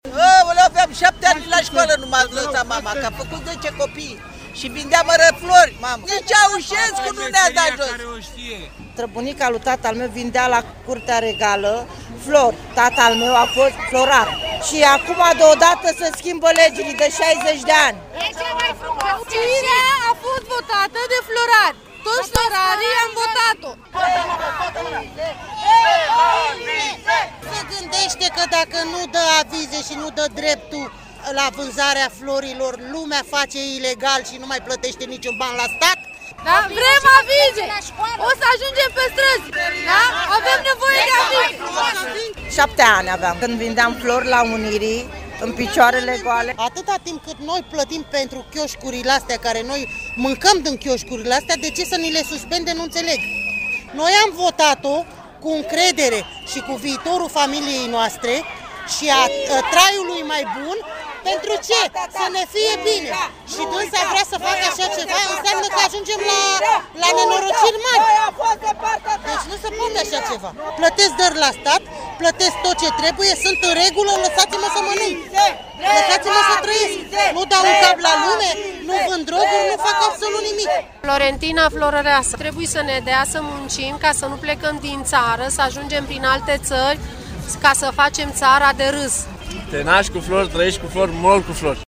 Un convoi vesel și colorat, cu flori și pancarte, a traversat Bulevardul Elisabeta.
Glasul fetelor e acoperit de vuvuzele, fluiere și scandări: Meseria noastră, e cea mai frumoasă! Meseria noastră, e cea mai frumoasă!
Și vocea ei e acoperită de scandări.